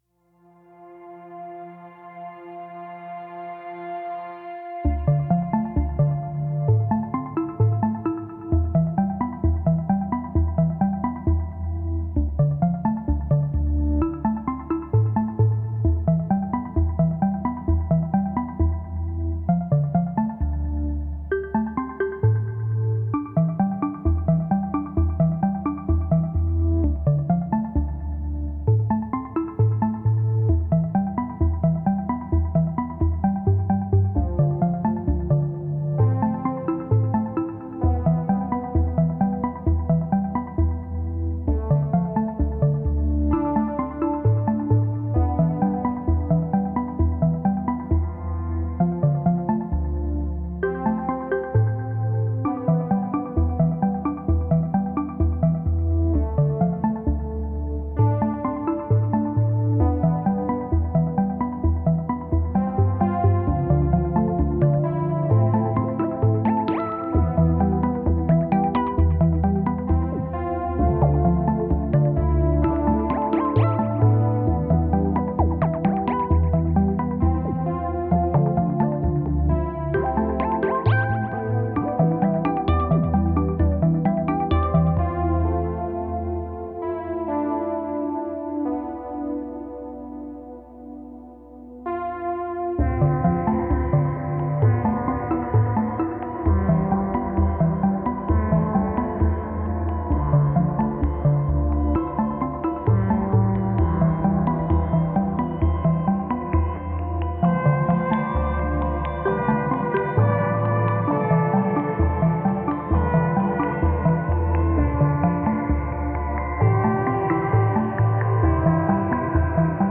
Thoughtful, tranquil, and introspective.